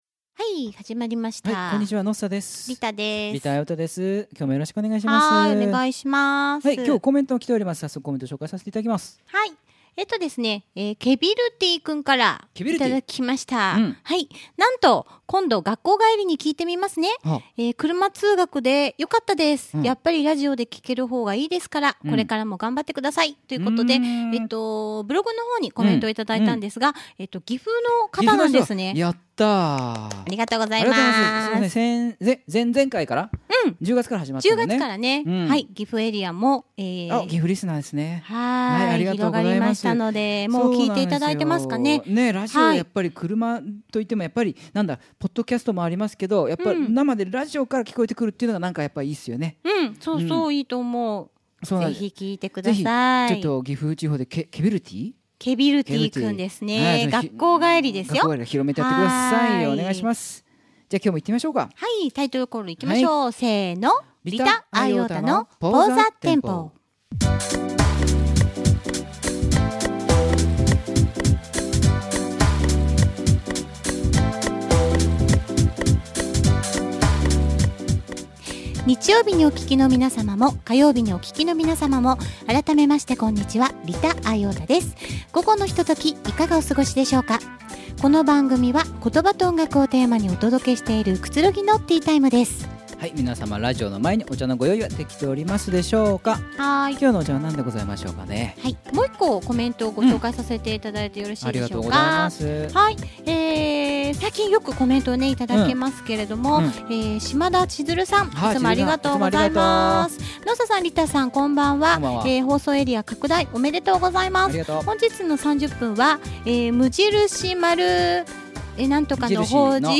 （尚、ストリーミング配信に関しましては、権利事情により、 ２曲目と３曲目はお聞き頂けませんのでご了承下さい。）